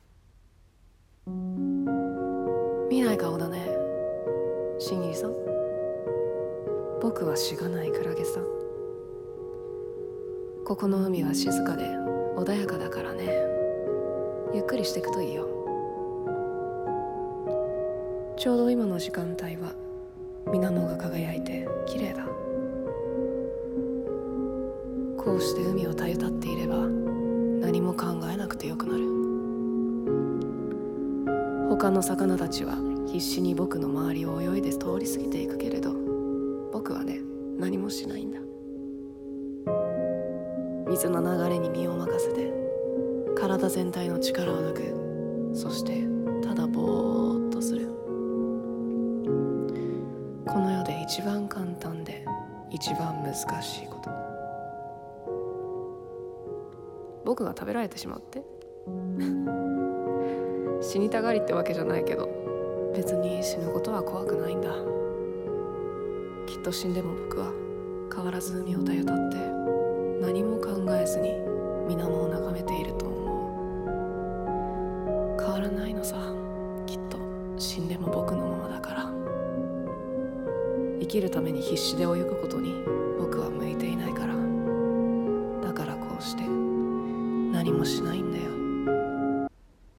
声劇 クラゲと紺色の海